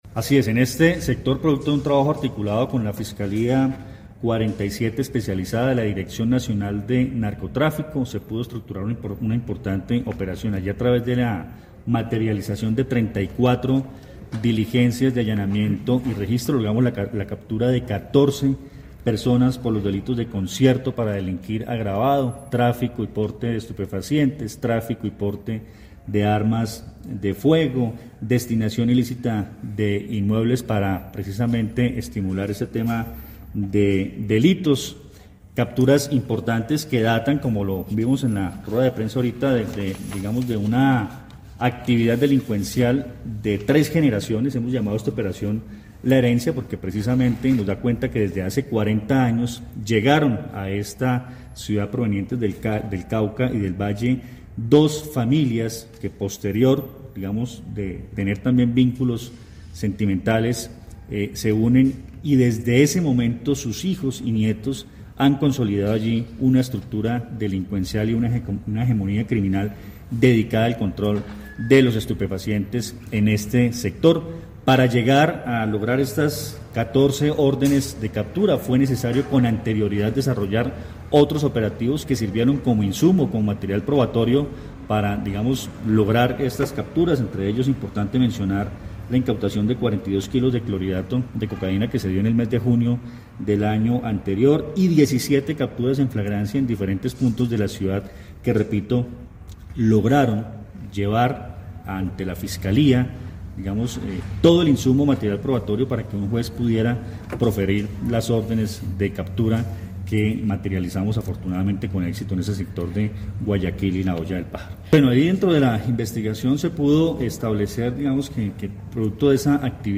Comandante de la Policía del Quindío, coronel Luis Fernando Atuesta